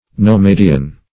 nomadian - definition of nomadian - synonyms, pronunciation, spelling from Free Dictionary Search Result for " nomadian" : The Collaborative International Dictionary of English v.0.48: Nomadian \No*ma"di*an\, n. A nomad.